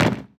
foot1.wav